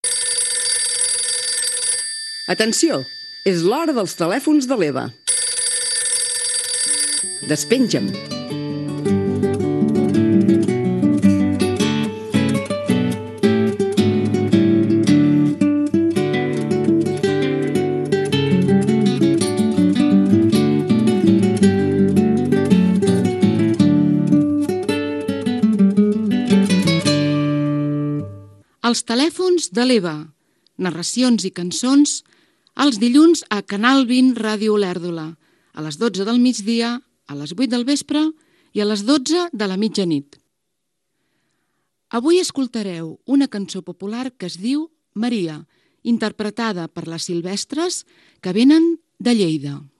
Careta del programa de narracions i cançons i presentació d'un tema musical
Entreteniment